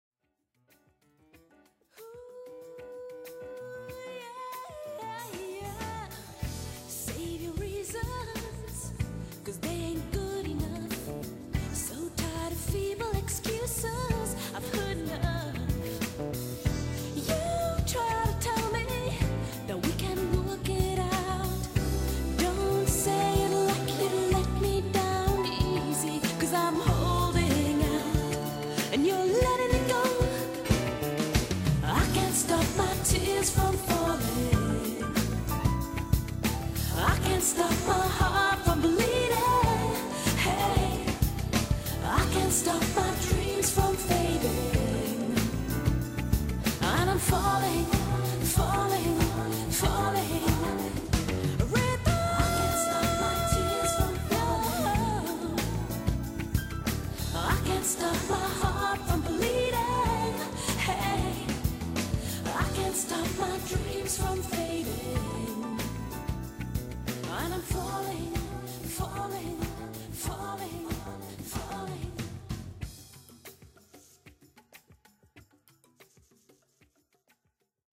【所屬類別】 CD唱片　　爵士及藍調
優雅的現代爵士樂風，韻味與品味的完美結合...